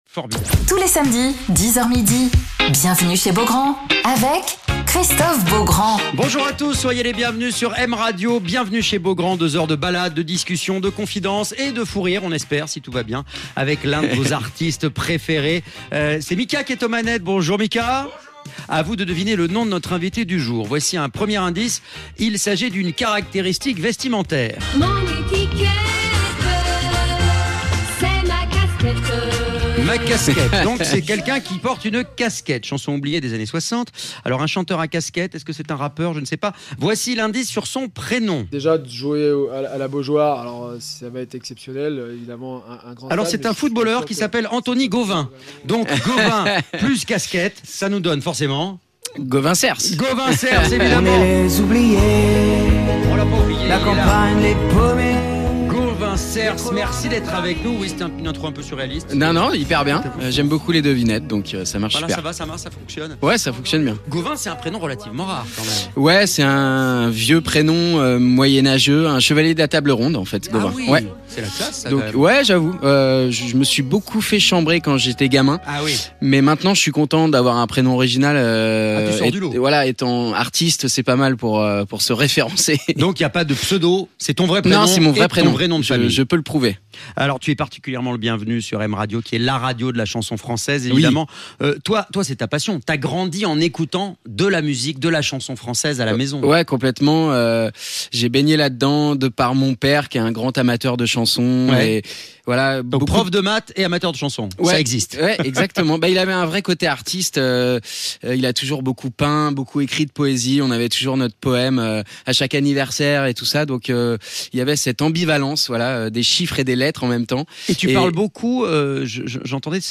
Alors qu'il prépare une grande tournée à l'automne, avec un passage par la mythique salle de l'Olympia (les 29 janvier et 26 novembre 2027), pour présenter son dernier album "Boulevard de l'Enfance", Gauvin Sers est l'invité de Christophe Beaugrand sur M Radio !